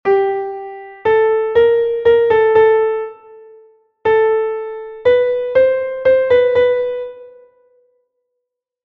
Verschiedene Theoristen [sic] sind gewohnt, diejenige Transposition eines kurzen melodischen Satzes, die einen Ton höher versetzt wird, mit dem Worte Rosalie zu bezeichnen.